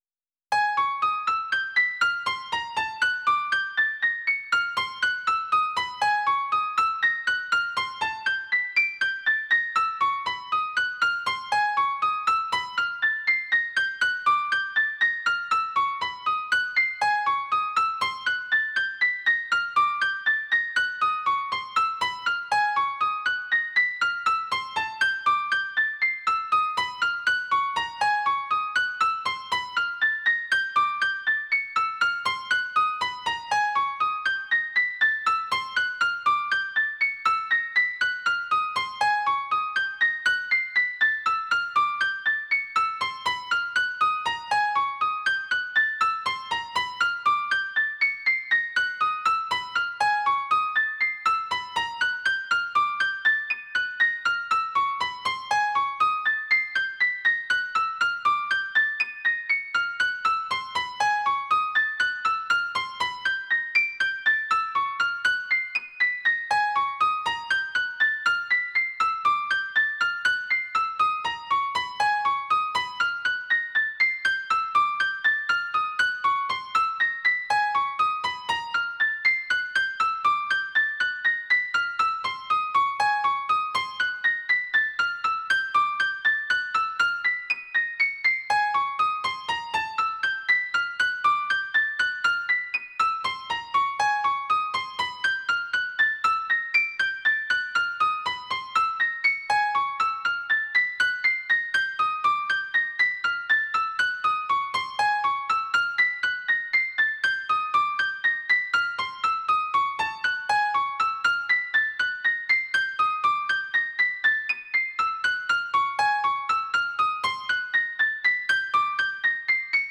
serial minimalistic algorithmic composition for player piano